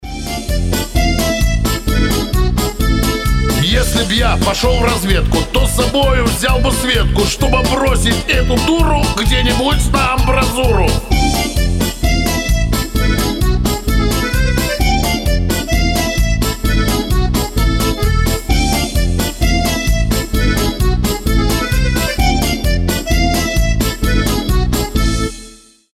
веселые
аккордеон
смешные
частушки